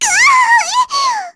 Lilia-Vox_Damage_kr_03.wav